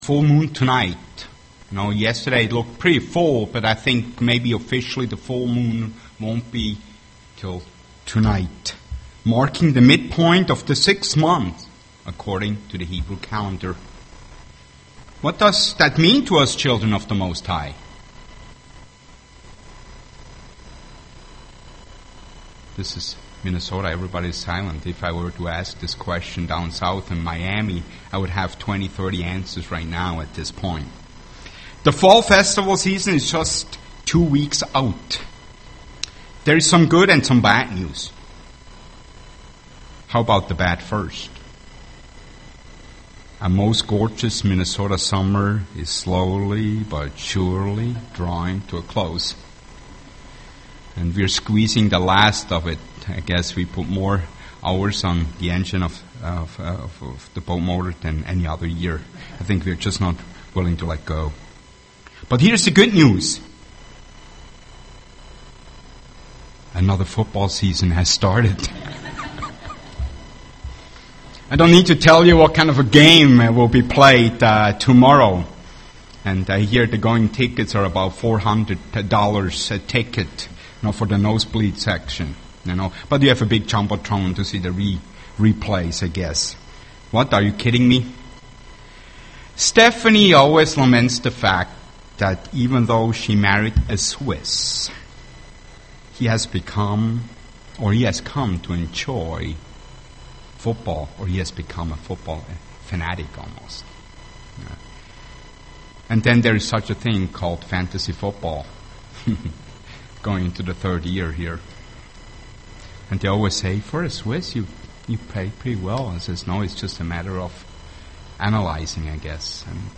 Given in Twin Cities, MN
UCG Sermon psalms deliverance Studying the bible?